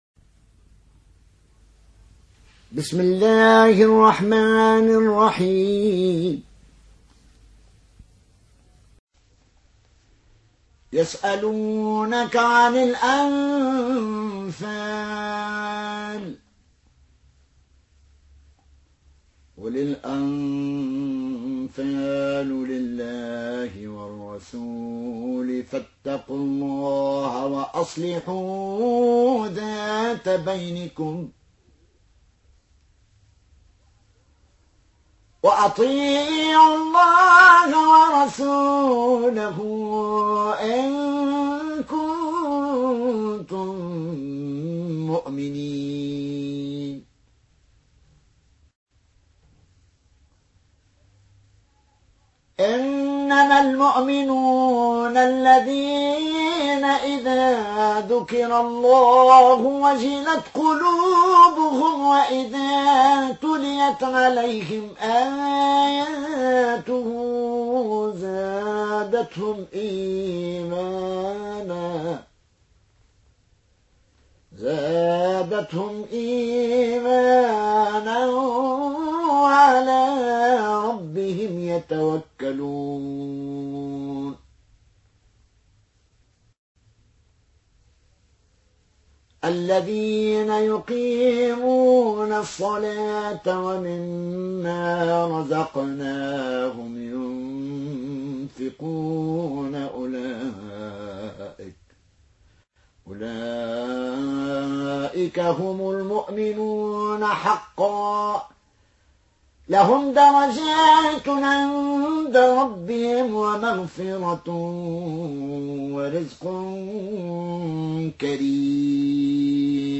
روایت فالون از نافع